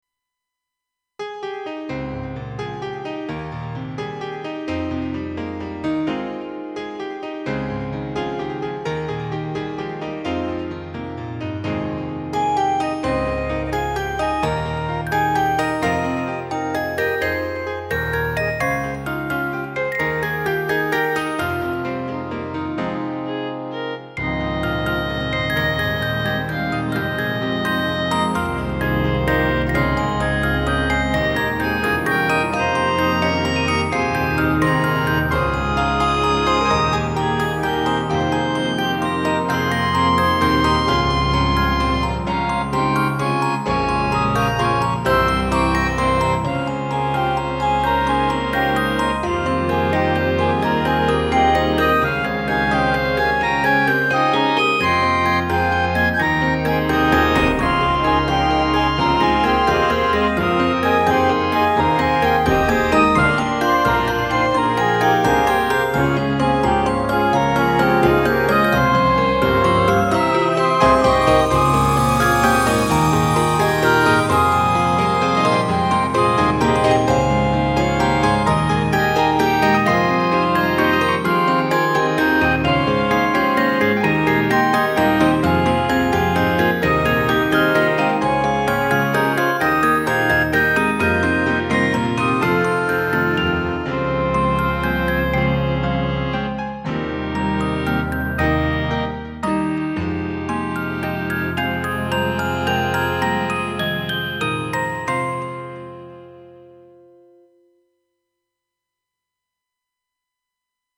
某「海の日DTM祭り」で好評だった、2006年度「址」をセルフアレンジしてみました。